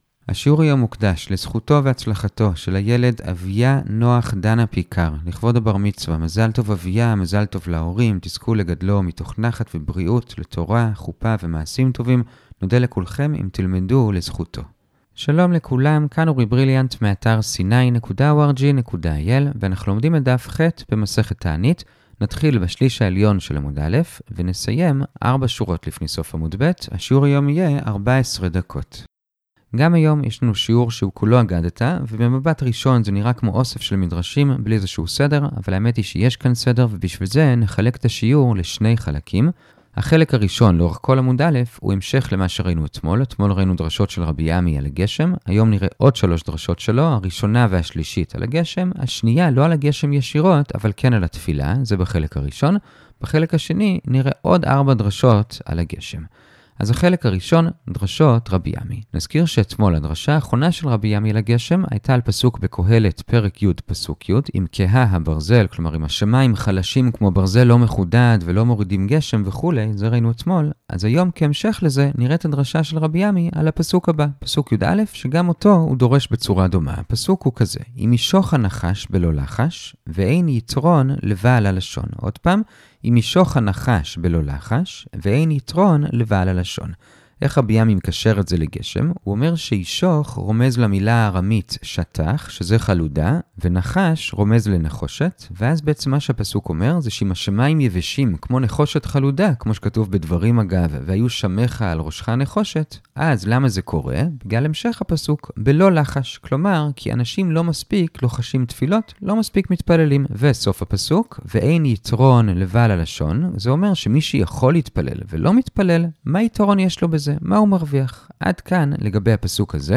שיעור הדף היומי הקצר